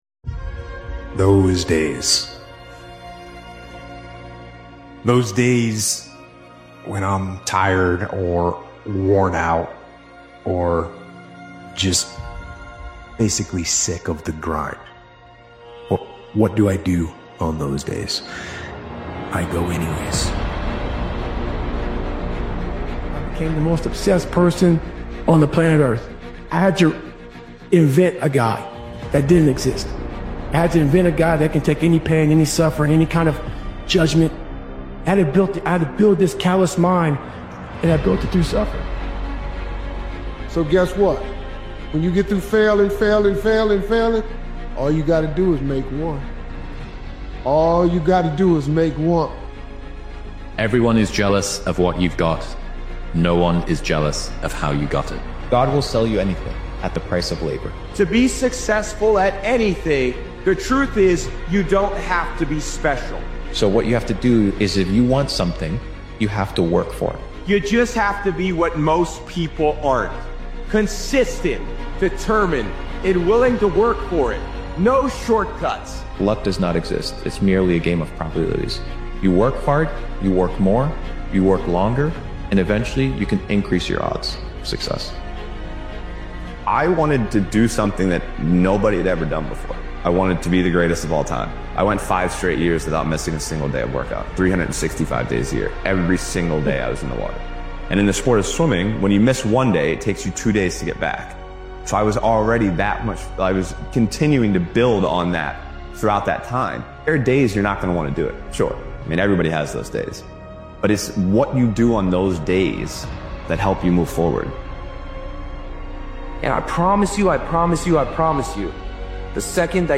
This powerful motivational speeches compilation pulls you back to the version of yourself you once envisioned—the standards you set, the goals you declared, the identity you committed to building.